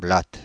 Ääntäminen
Paris
IPA: /blat/